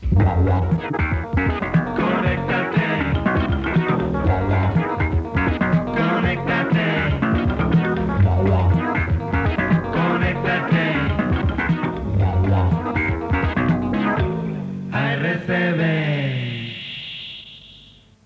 Indicatiu de l'emissora (jingle).